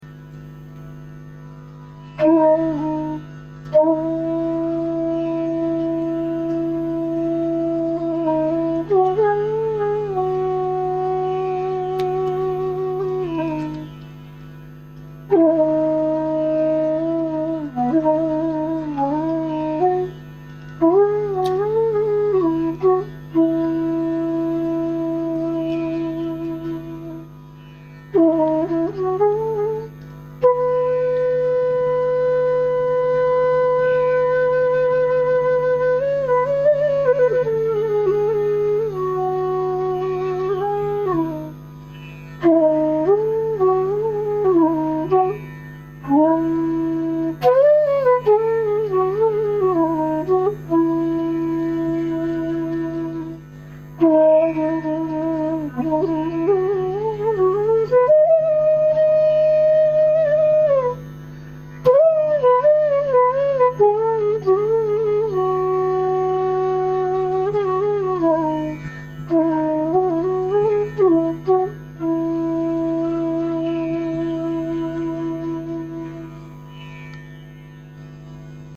E Base flute professional